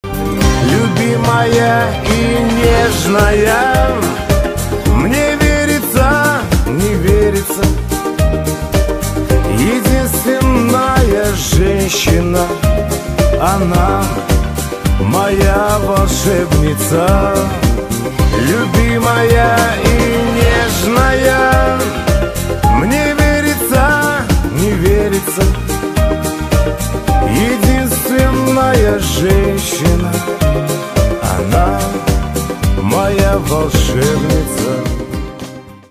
мужской вокал
русский шансон